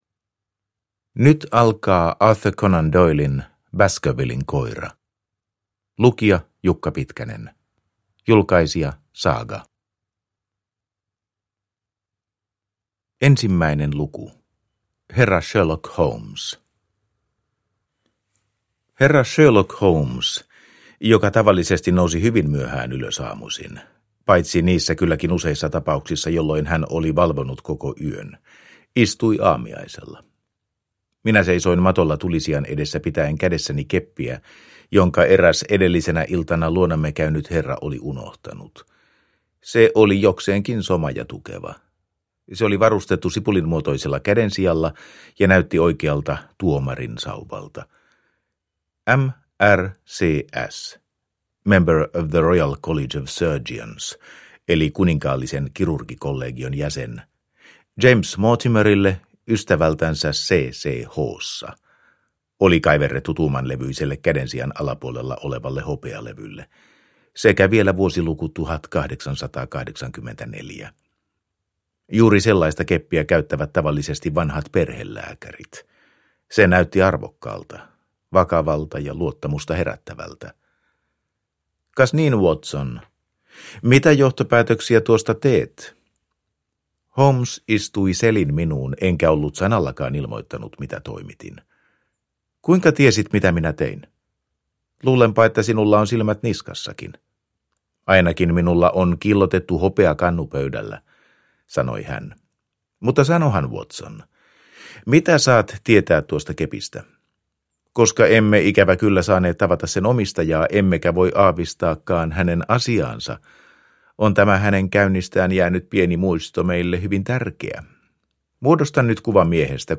Baskervillen koira – Ljudbok – Laddas ner